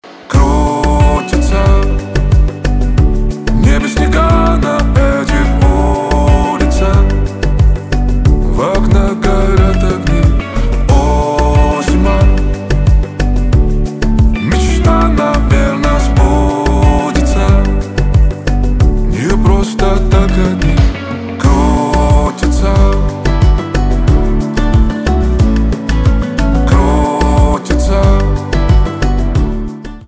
поп
гитара
чувственные